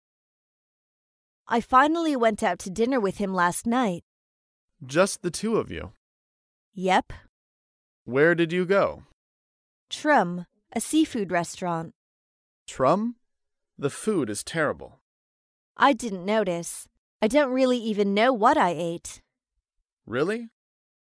在线英语听力室高频英语口语对话 第162期:恋爱魔力的听力文件下载,《高频英语口语对话》栏目包含了日常生活中经常使用的英语情景对话，是学习英语口语，能够帮助英语爱好者在听英语对话的过程中，积累英语口语习语知识，提高英语听说水平，并通过栏目中的中英文字幕和音频MP3文件，提高英语语感。